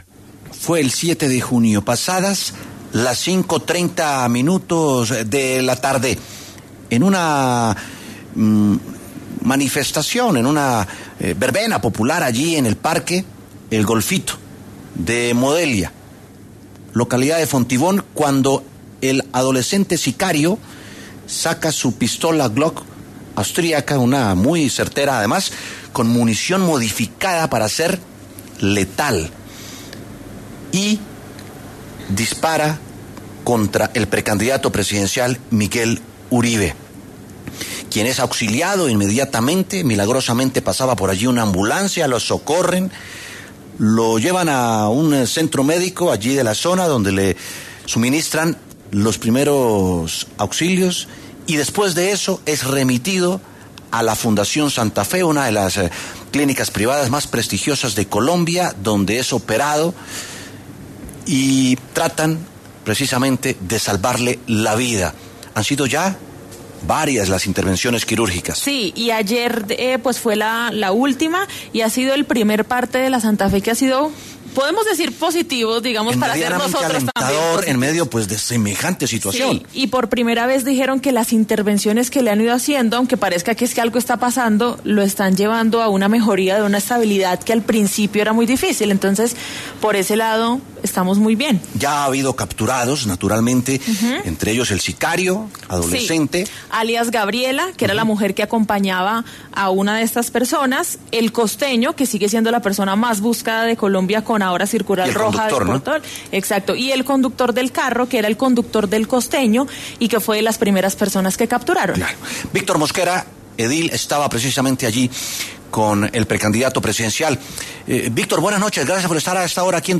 Víctor Mosquera, edil de Fontibón, habló en W Sin Carreta sobre el atentado del que fue víctima Miguel Uribe el pasado 7 de junio en Modelia.